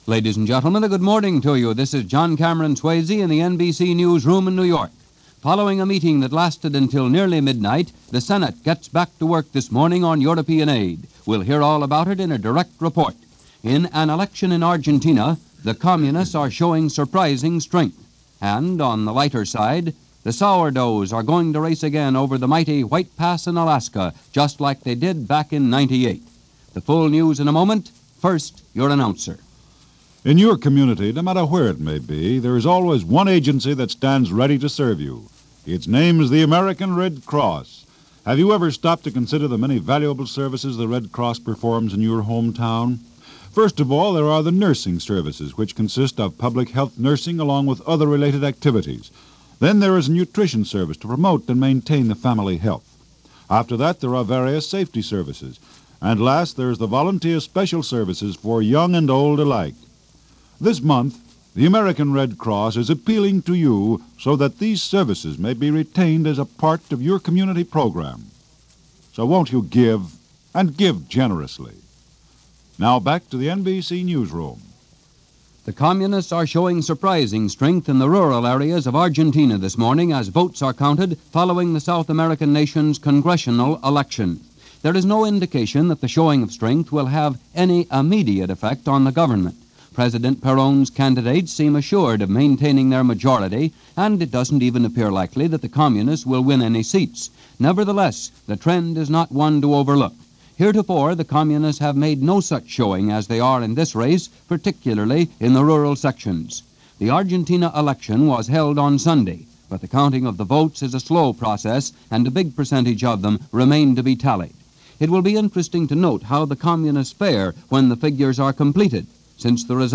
News for this day in 1948.